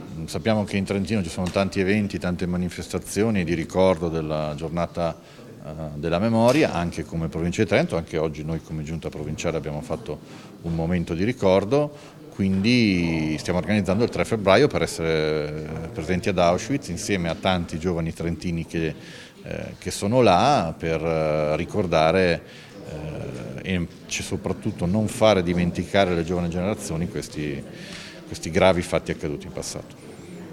Intervista al presidente Fugatti: